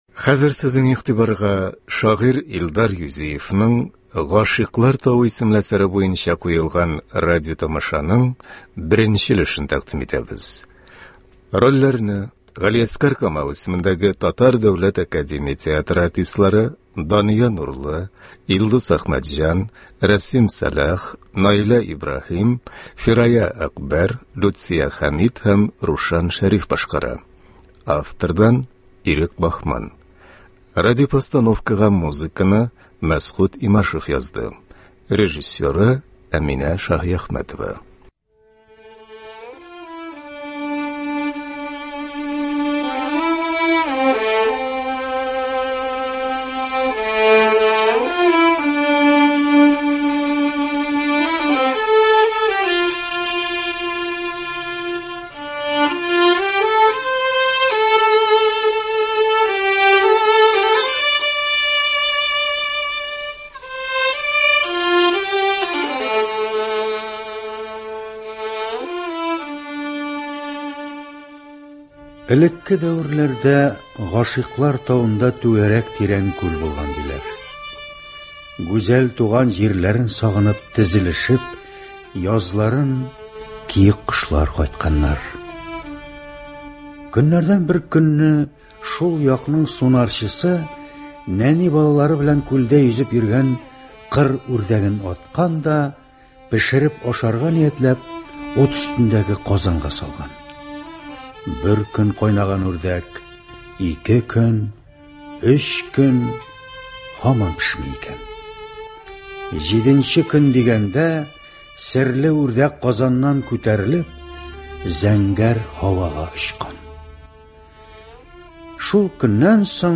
“Гашыйклар тавы”. Радиотамаша.
Радиотамаша магнитофон тасмасына Татарстан радиосы студиясендә 1990 елларда язып алынган.